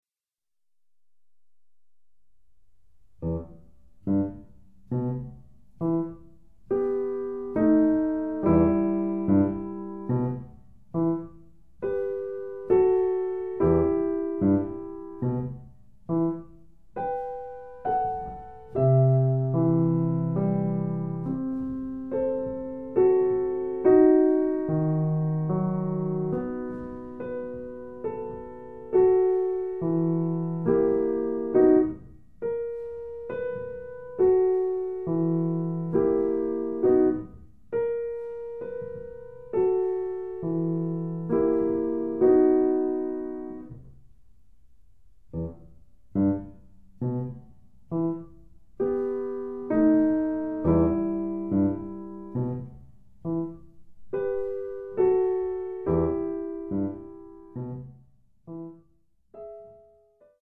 非常にゆっくりと弾かれた
2013年11月29日　サウンドアーツ（都立大）にて収録
ピアノ：Steinway&Sons D-274